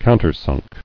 [coun·ter·sunk]